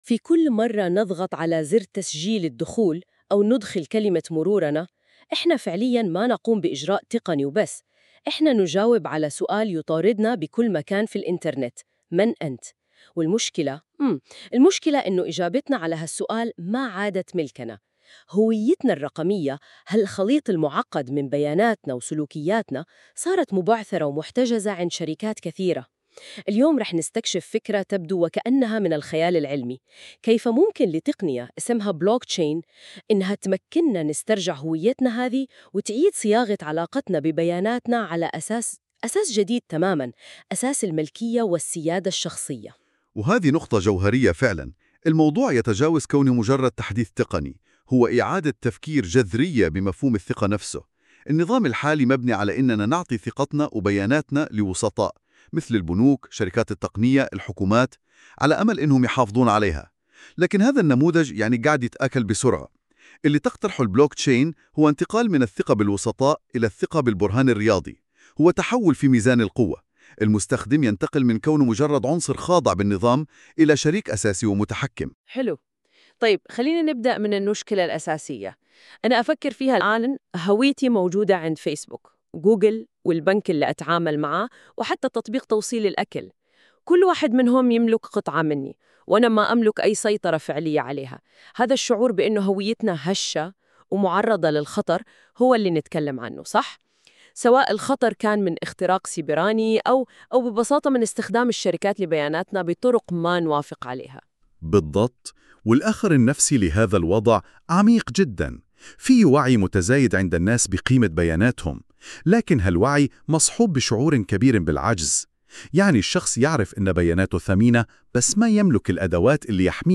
يمكنك الاستماع إلى هذا المقال بدلاً من قراءته عبر المقطع الصوتي التالي👇